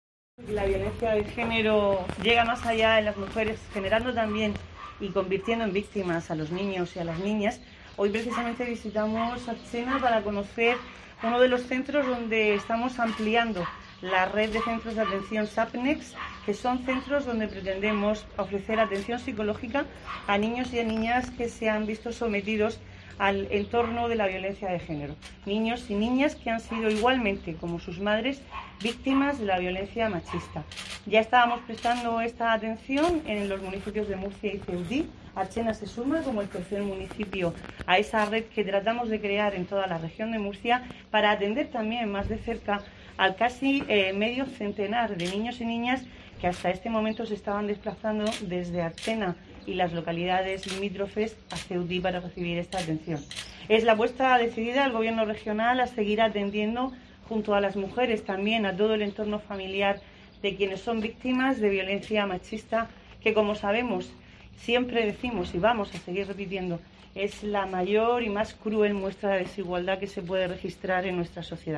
Isabel Franco, vicepresidenta y consejera de Mujer, Igualdad, LGTBI, Familias y Política Social